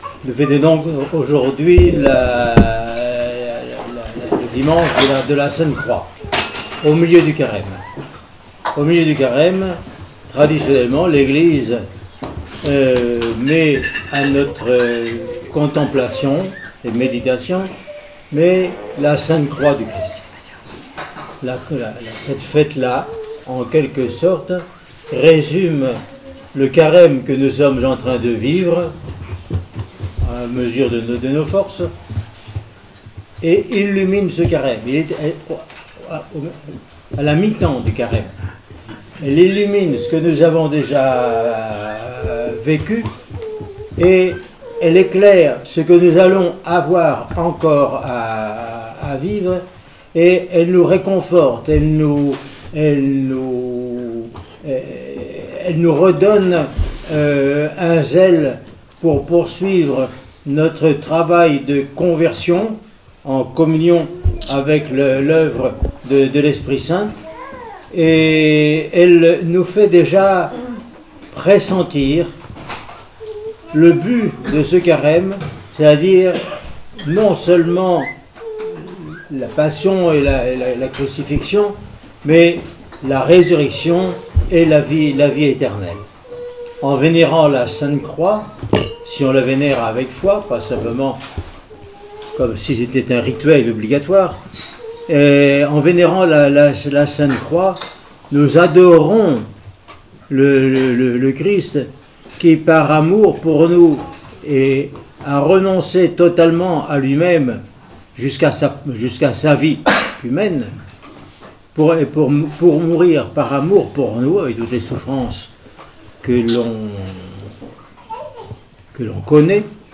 Homélie sur la Croix :Monastère de la Transfiguration